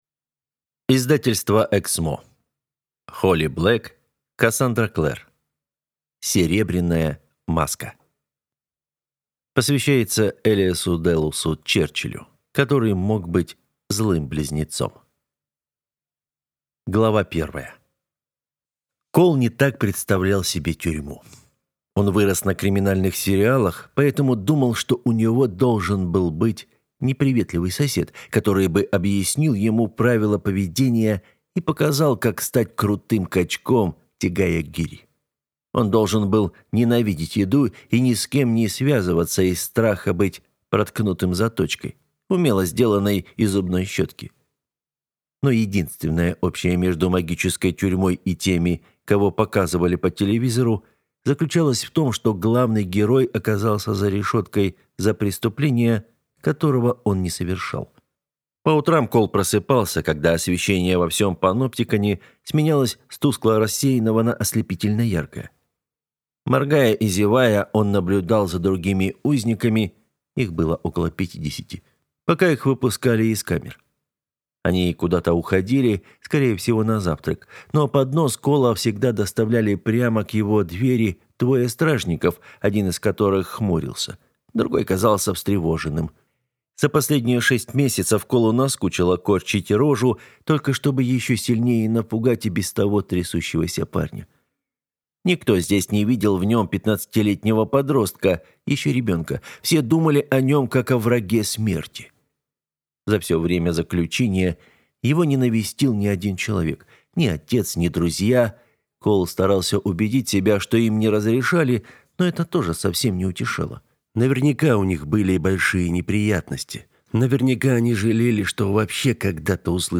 Аудиокнига Серебряная маска | Библиотека аудиокниг